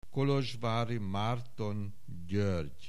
Aussprache Aussprache